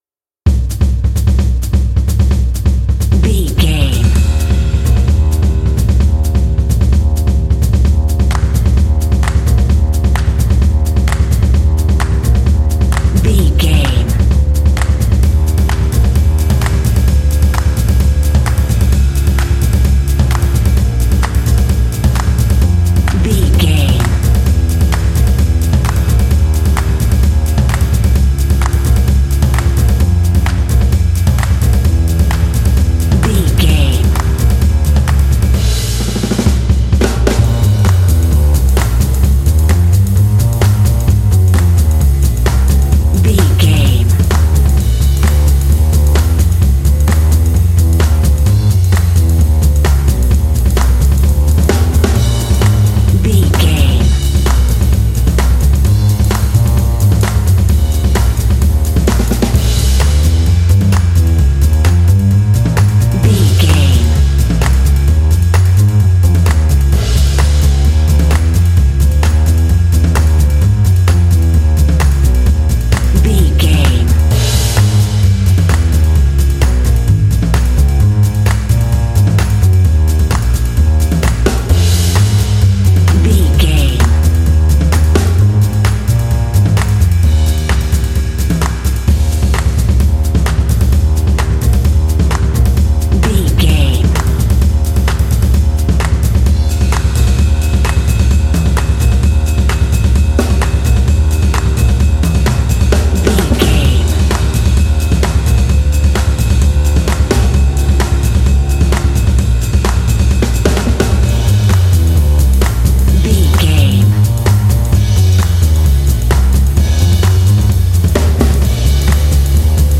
Uplifting
Mixolydian
B♭
energetic
lively
cheerful/happy
drums
double bass
percussion
big band
jazz